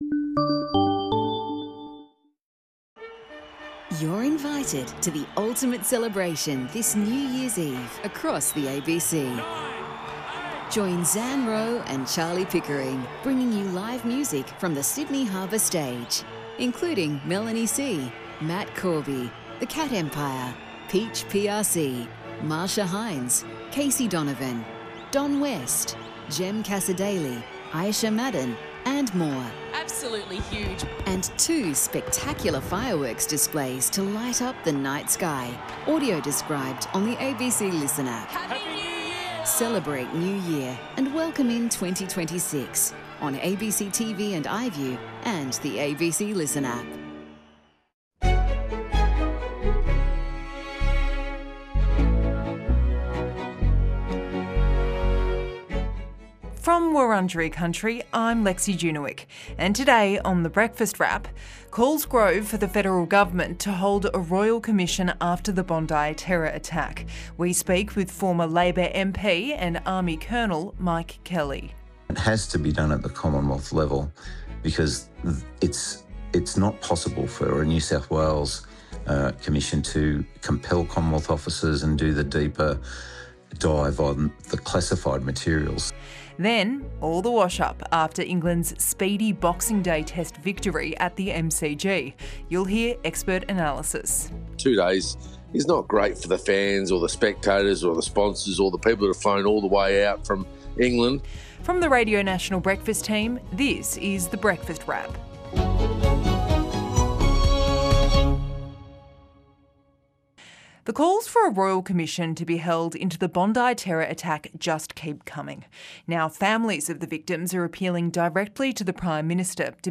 Radio National Breakfast is Australia's only daily national radio current affairs program, synonymous with agenda-setting news coverage, breaking news and a place where you will hear the most significant stories impacting the lives of all Australians wherever they live.